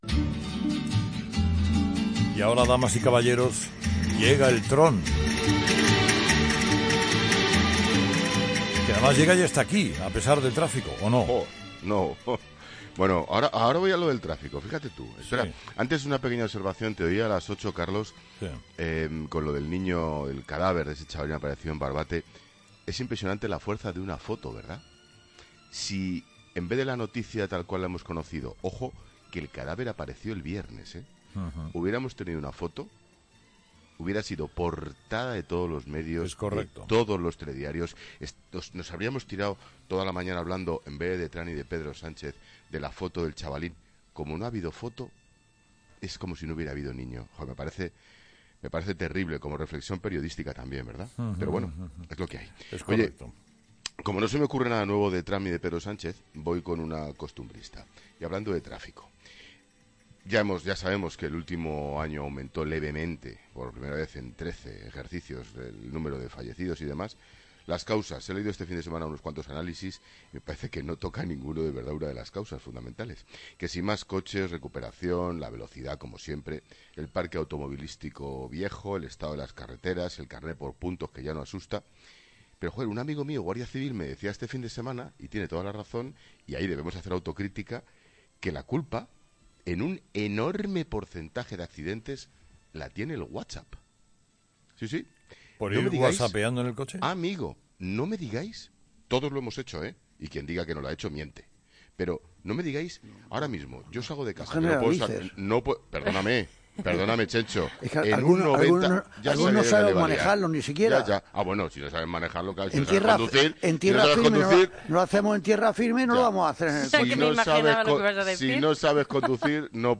Ángel Expósito hace su paseíllo en 'Herrera en COPE'